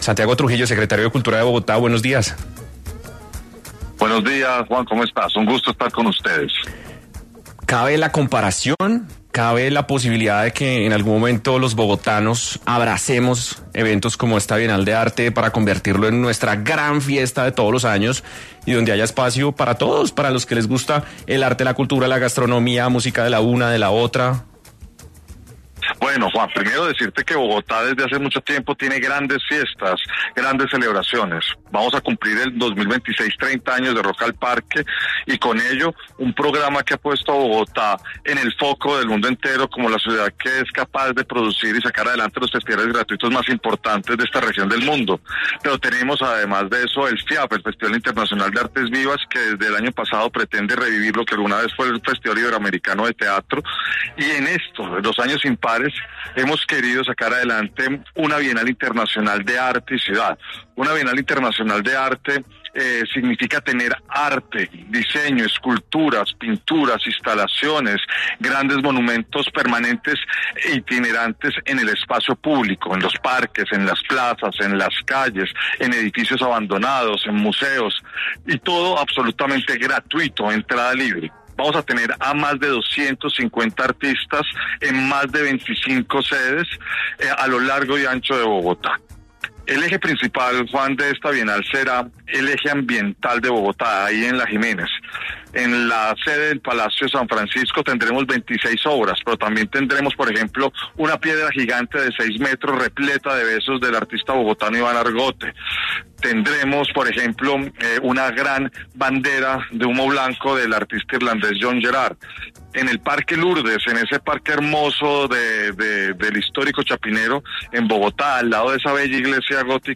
El secretario de Cultura de Bogotá, Santiago Trujillo, habló en 6AM de Caracol Radio sobre la llegada de esta galería internacional a la capital del país.